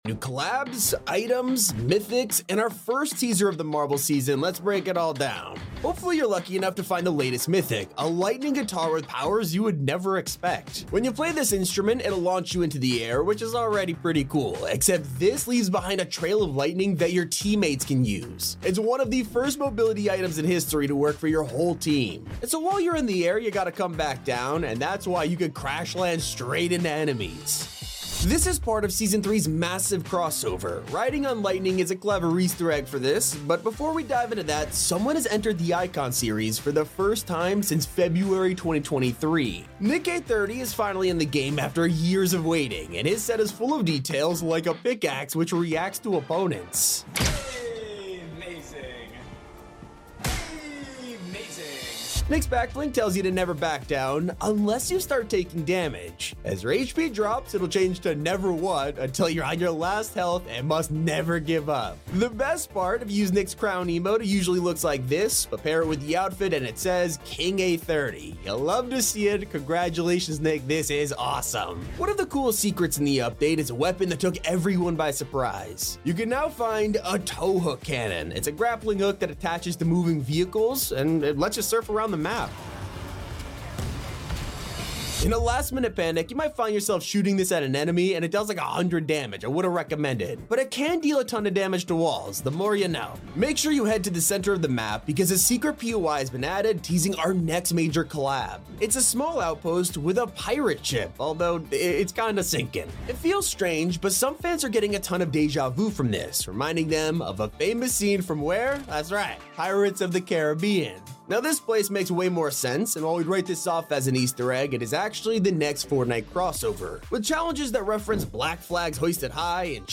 Credit: top 5 gaming sound effects free download